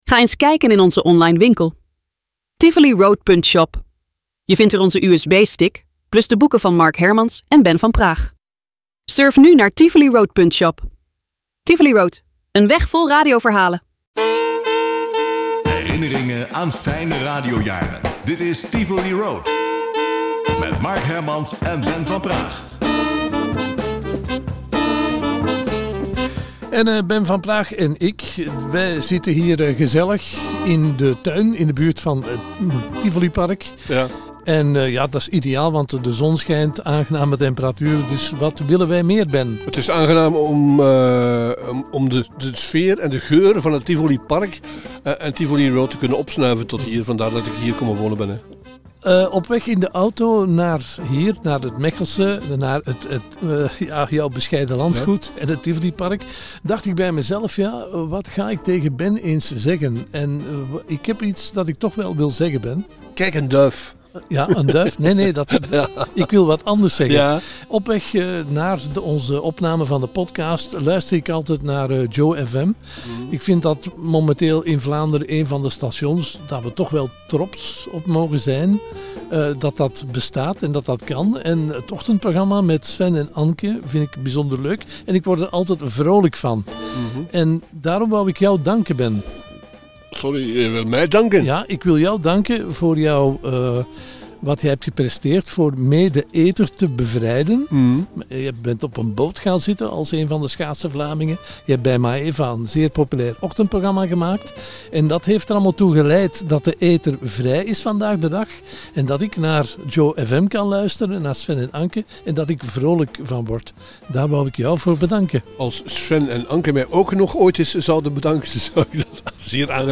Tijdens hun wandelingen in het Tivolipark